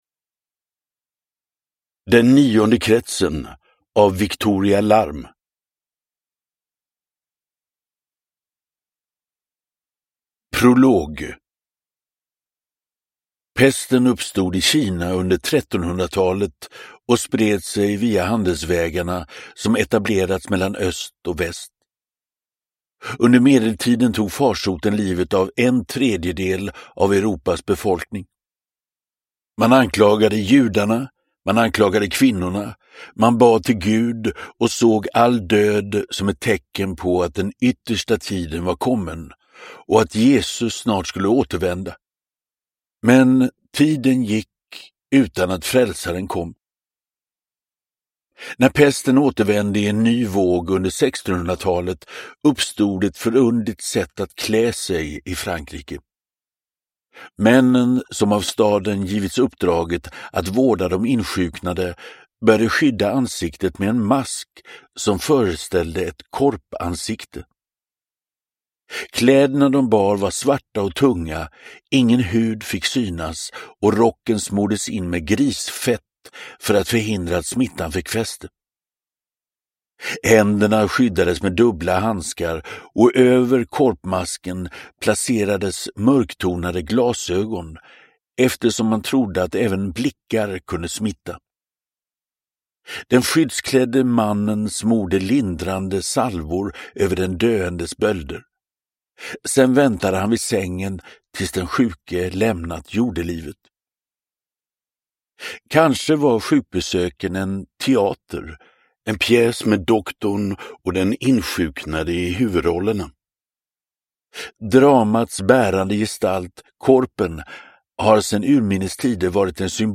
Den nionde kretsen – Ljudbok – Laddas ner
Uppläsare: Magnus Roosmann